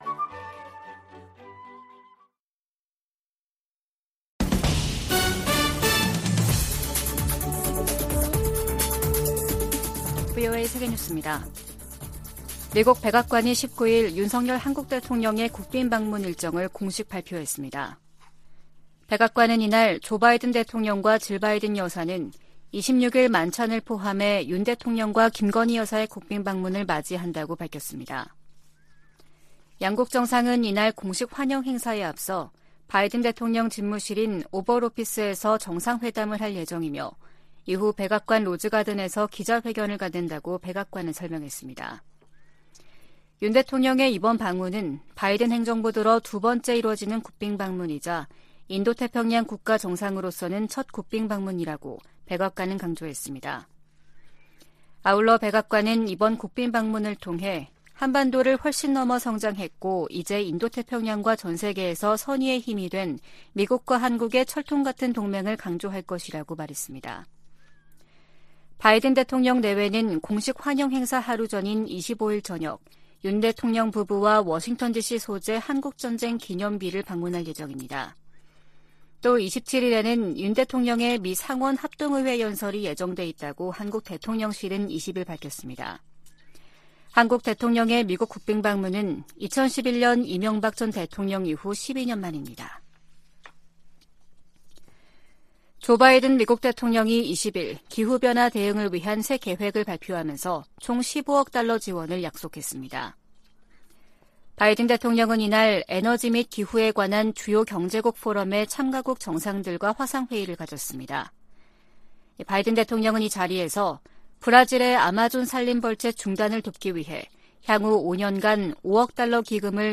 VOA 한국어 아침 뉴스 프로그램 '워싱턴 뉴스 광장' 2023년 4월 21일 방송입니다. 백악관은 윤석열 한국 대통령의 방미 일정을 밝히고, 26일 오벌 오피스에서 조 바이든 대통령과 회담한다고 발표했습니다. 미 국무부는 북한의 불법적인 대량살상무기(WMD) 개발 자금을 계속 차단할 것이라고 밝혔습니다.